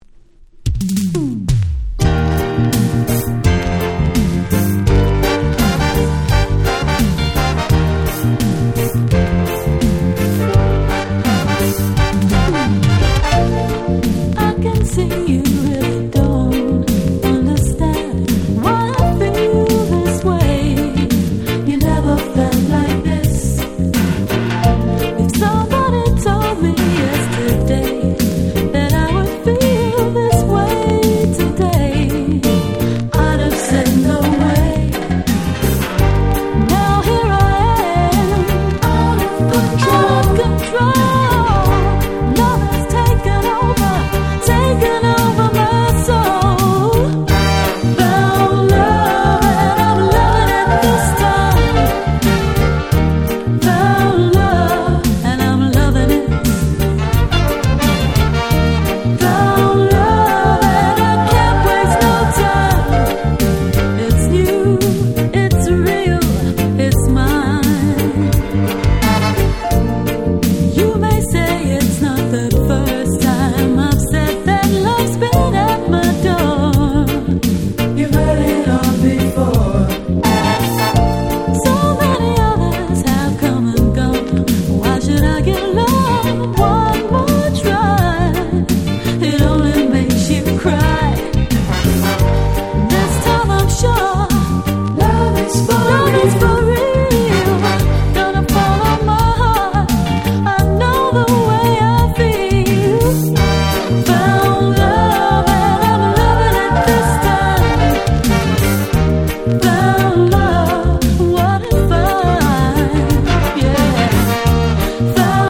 89' Dancehall Reggae Super Classics !!